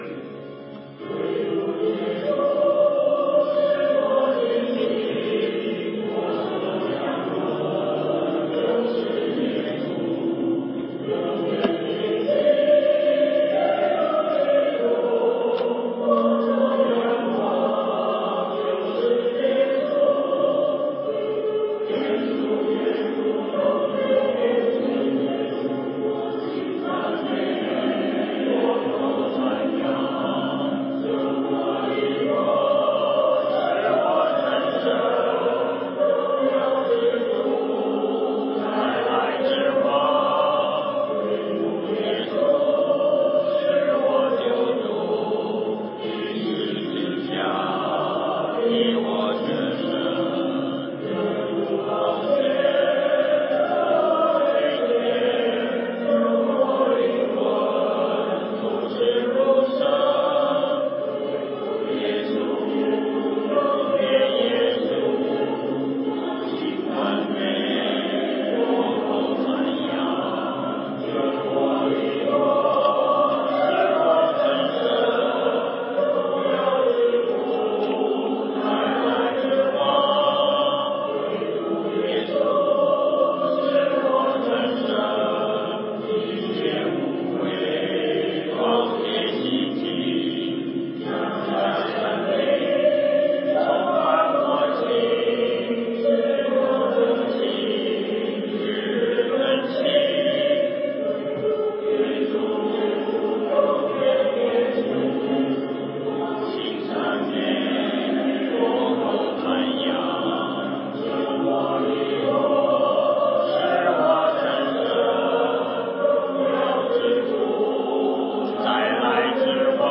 7-21-24敬拜-CD.mp3